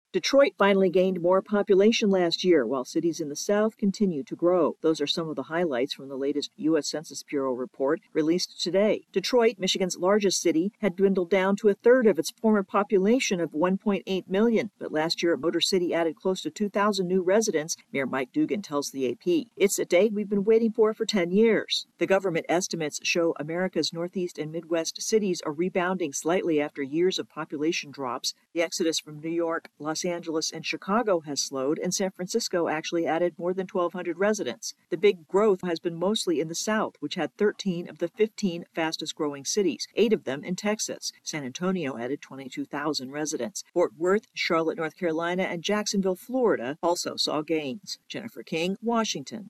reports with highlights from the government's new U.S. population estimates.